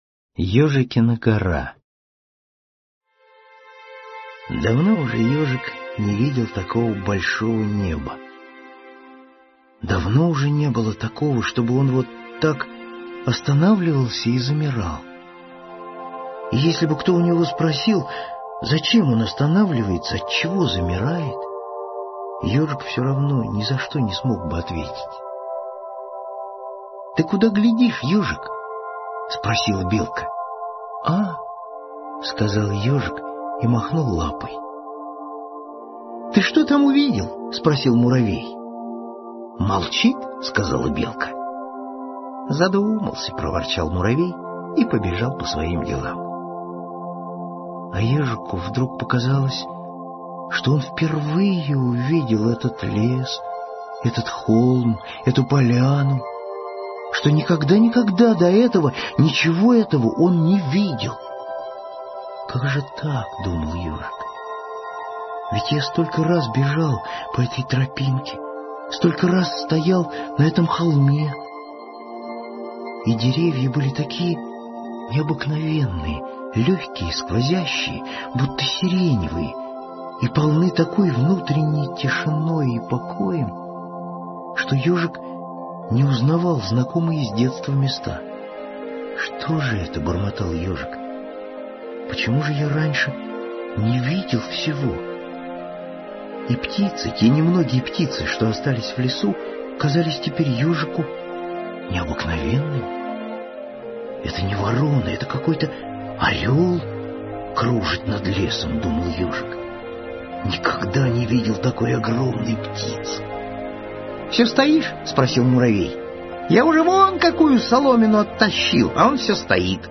Ёжикина гора – Козлов С.Г. (аудиоверсия)
Аудиокнига в разделах